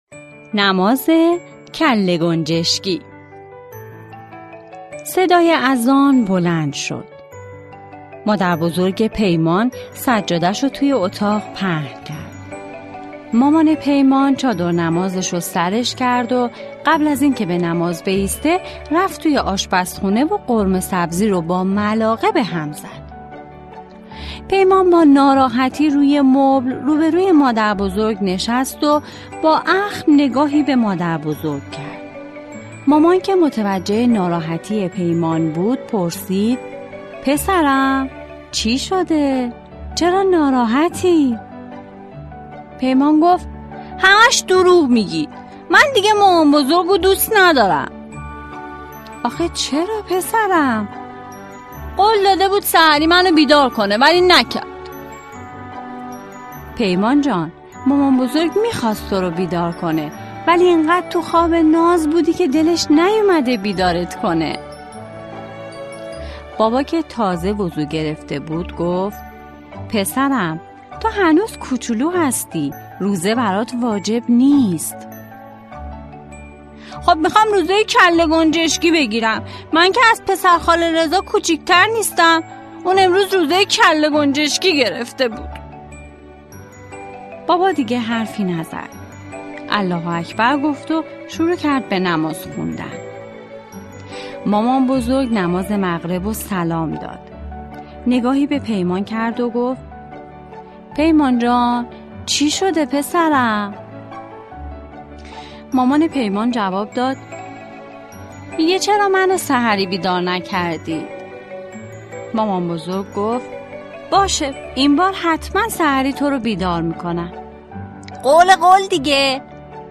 قصه کودکانه